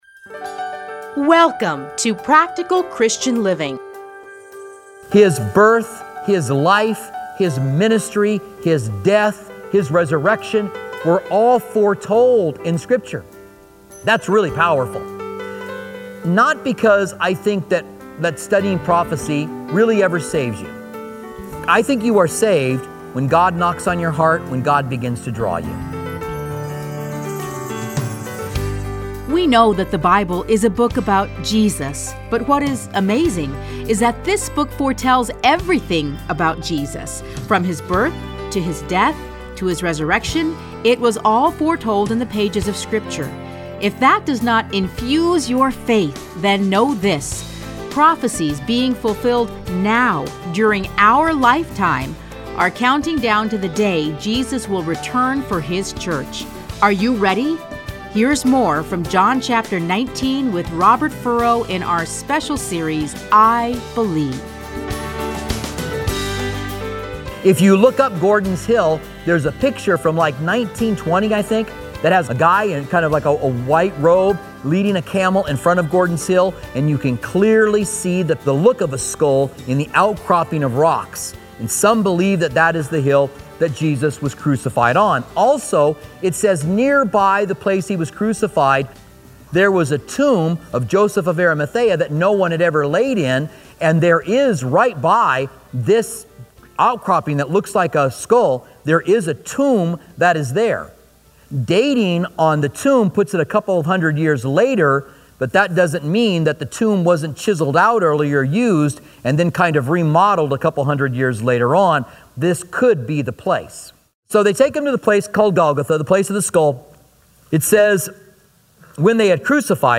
Listen to a teaching from John 19:17-30 .